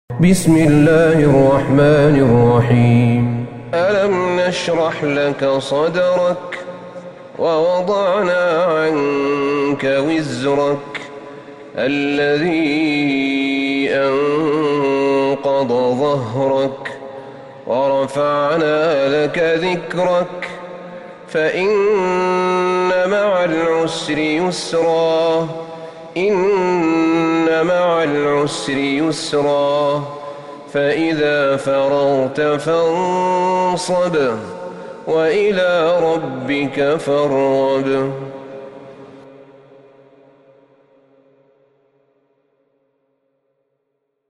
سورة الشرح Surat Ash-Sharh > مصحف الشيخ أحمد بن طالب بن حميد من الحرم النبوي > المصحف - تلاوات الحرمين